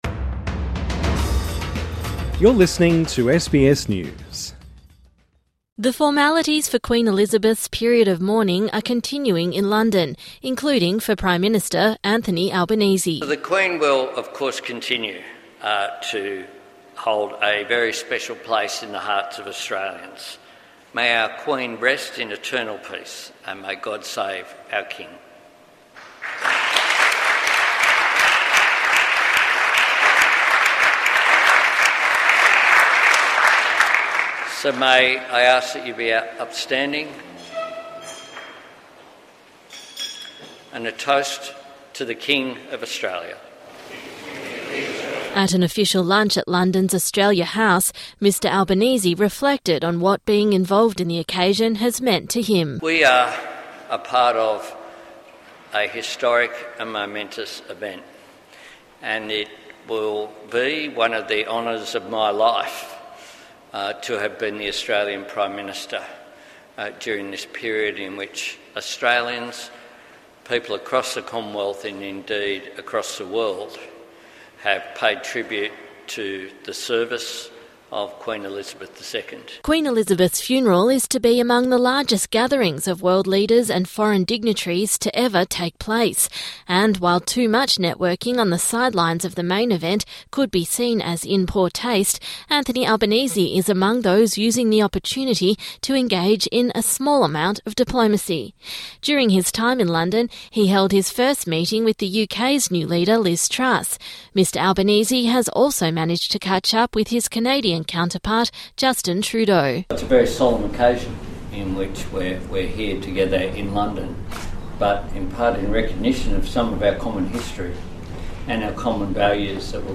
Anthony Albanese has led a toast to King Charles at a lunch at Australia House in London on the eve of the Queen's funeral.